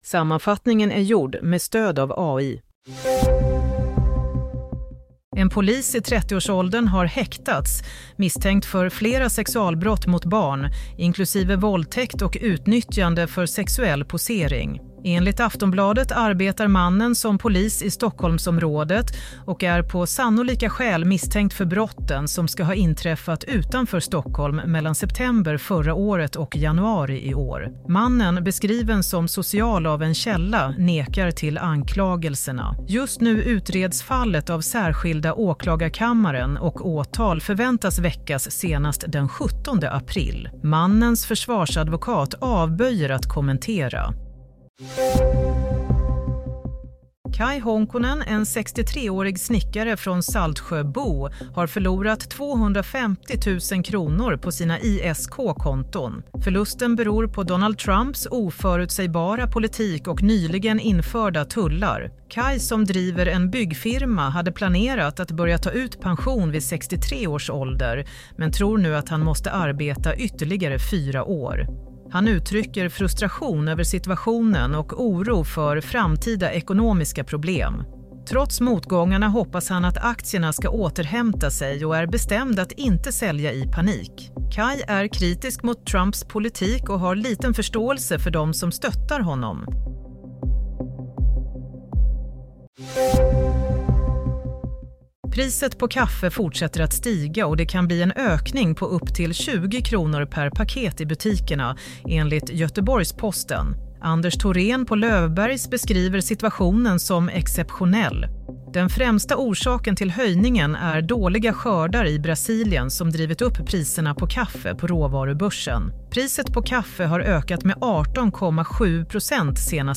Nyhetssammanfattning - 5 april 16:00
Sammanfattningen av följande nyheter är gjord med stöd av AI.